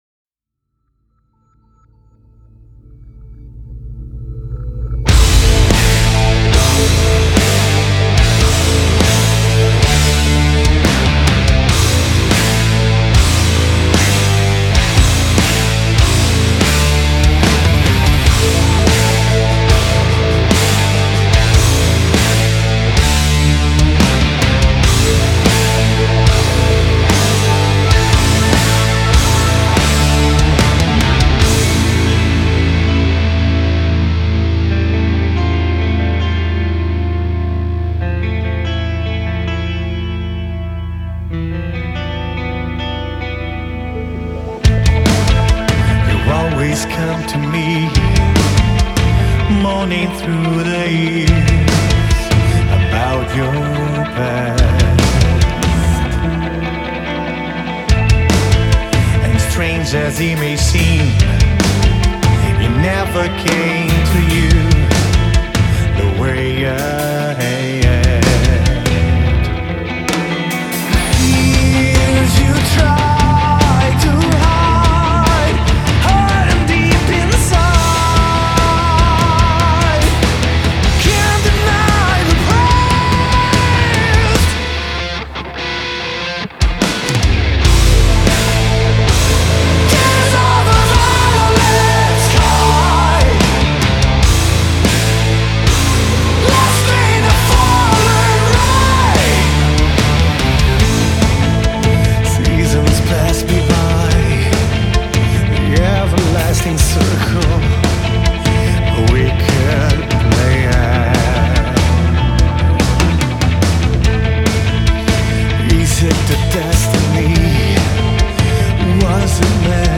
Genre: Metal
Recorded at Fascination Street Studios.